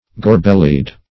Gor-bellied \Gor"-bel`lied\, a.
gor-bellied.mp3